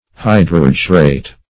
Search Result for " hydrargyrate" : The Collaborative International Dictionary of English v.0.48: Hydrargyrate \Hy*drar"gy*rate\ (h[-i]*dr[aum]r"j[i^]*r[asl]t), a. Of or pertaining to mercury; containing, or impregnated with, mercury.
hydrargyrate.mp3